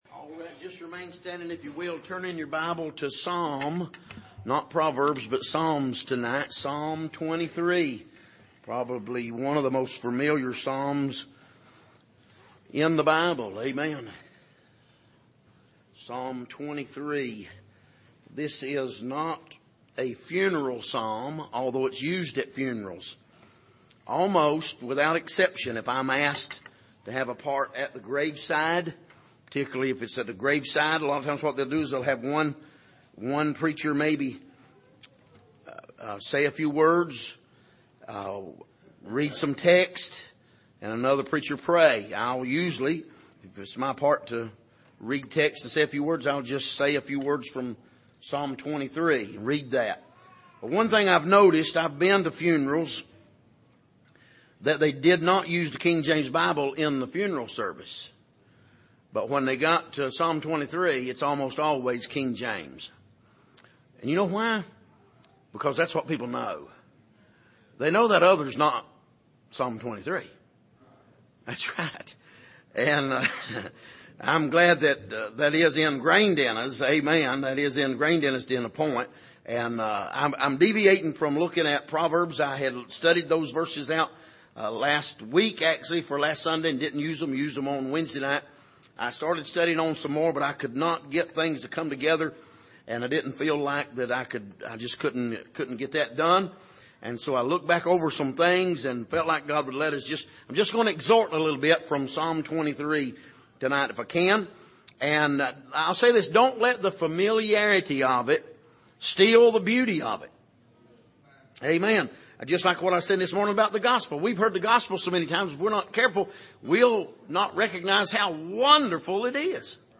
Passage: Psalm 23:1-6 Service: Sunday Evening